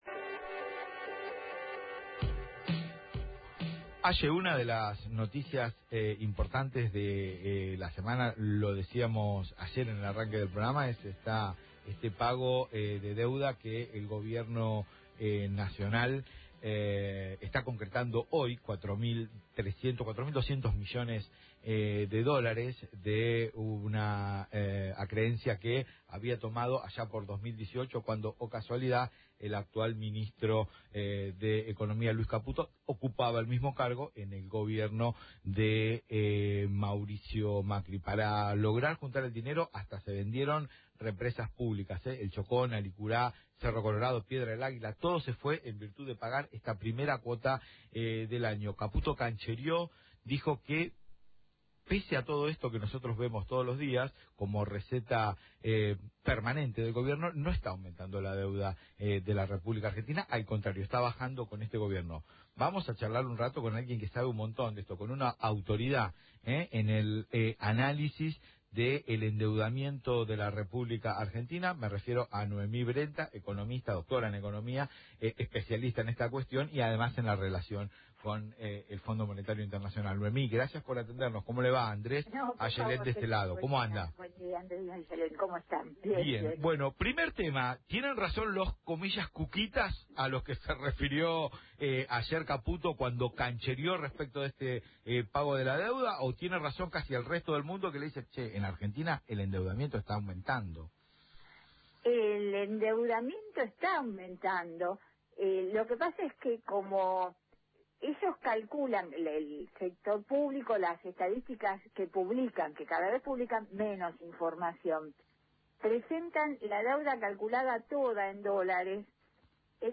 habló por Es un Montón en Radio Provincia